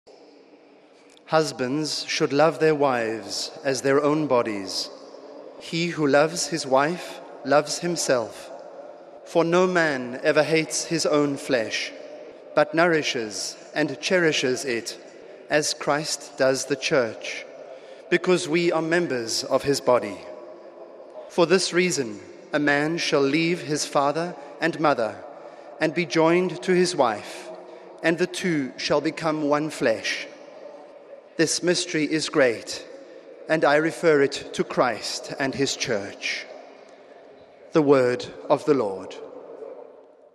The general audience of April 2, was held in the open in Rome’s St. Peter’s Square. It began with aides reading a passage from the Letter of St. Paul to the Ephesians in several languages.